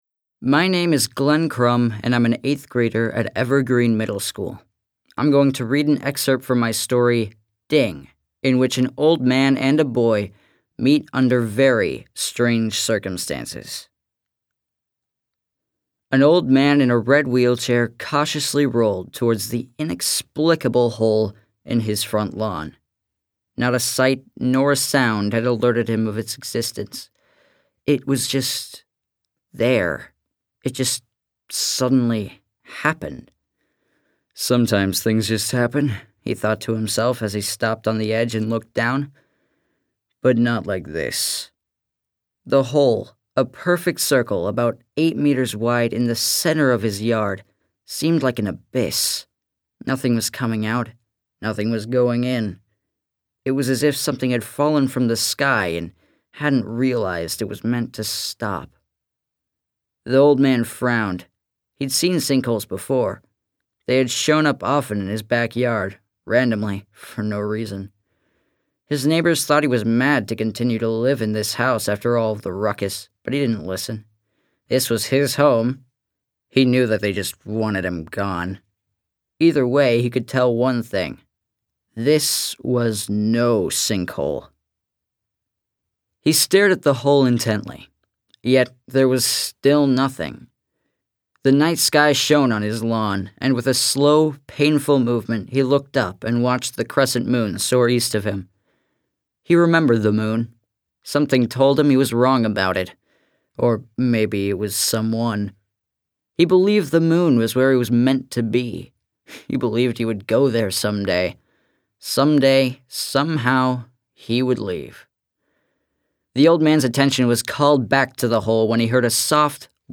This year, MoPop and Jack Straw Cultural Center collaborated to provide winners of their annual Write Out of This World Writing Contest with a unique experience: Winners participated in a writing workshop, voice workshop, and recording session at Jack Straw, resulting in a professional recording of each writer reading their work.